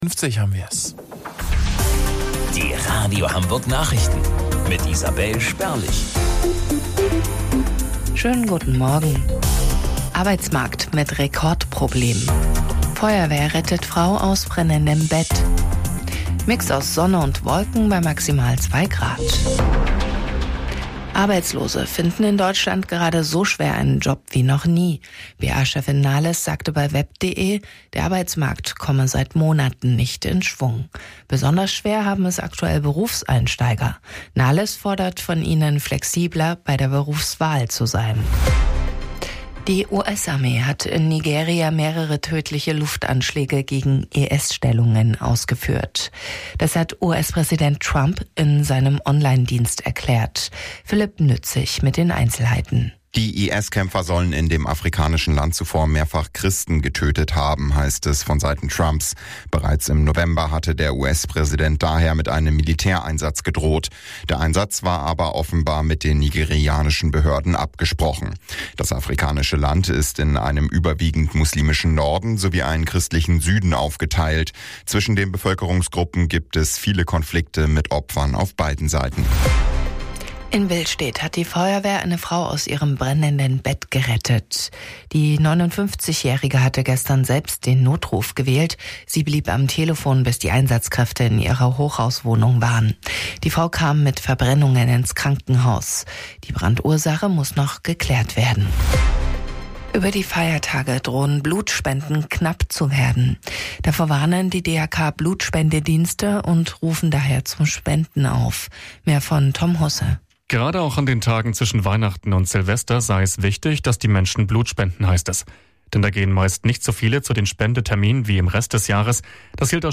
Radio Hamburg Nachrichten vom 26.12.2025 um 09 Uhr